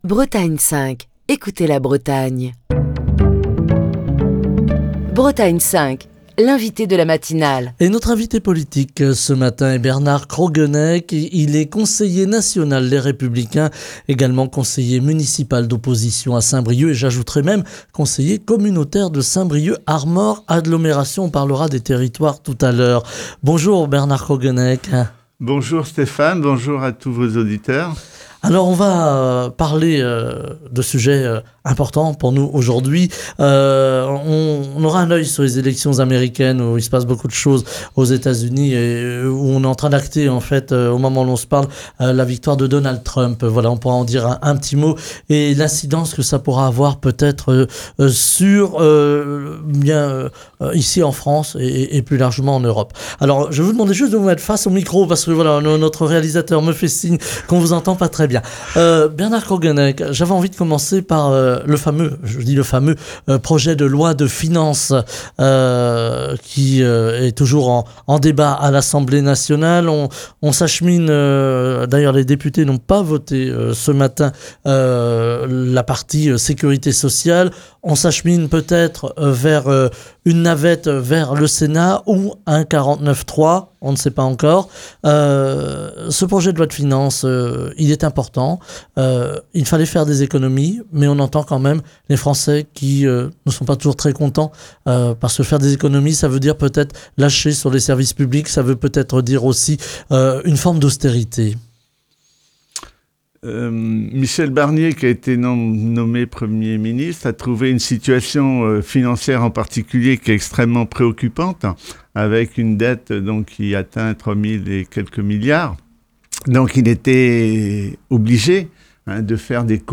Ce mercredi, Bernard Croguennec, conseiller national Les Républicains, conseiller municipal d'opposition à Saint-Brieuc, conseiller communautaire de Saint-Brieuc Armor Agglomération, est l'invité politique de Bretagne 5 Matin.